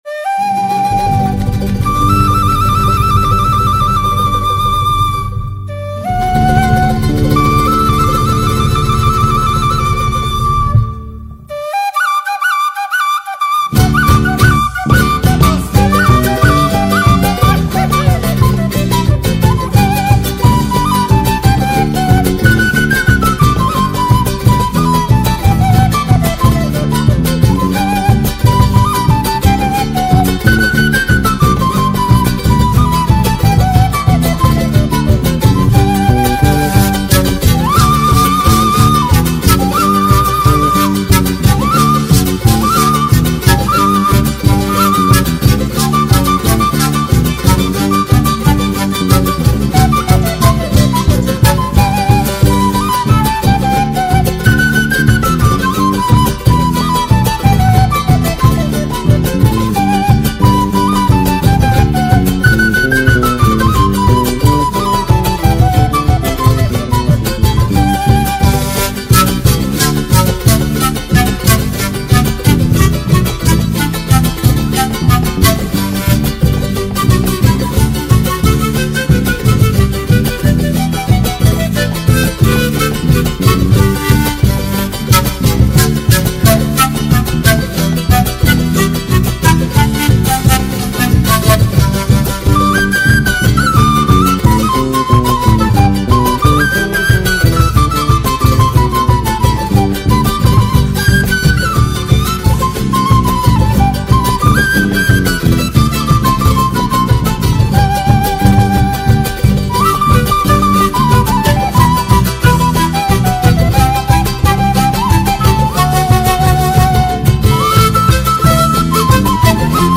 GALOPERA